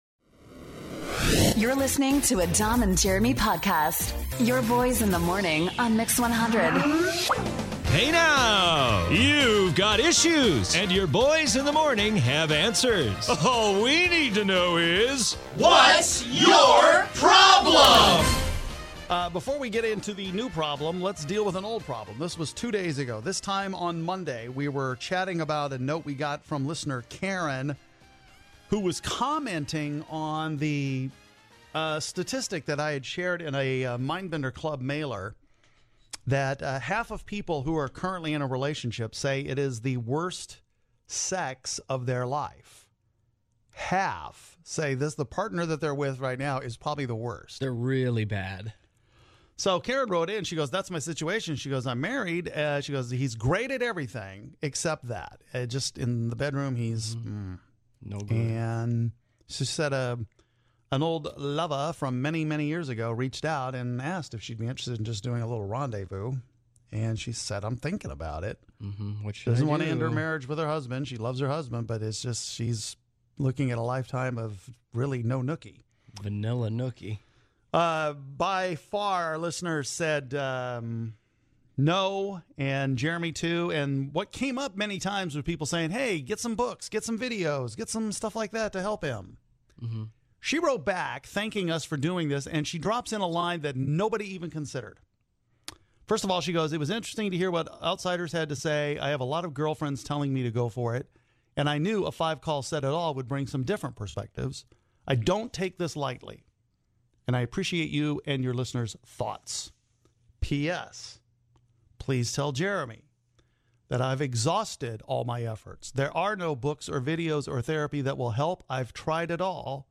Is it appropriate to plan a wedding over a holiday? We discuss here and hear from you the listeners.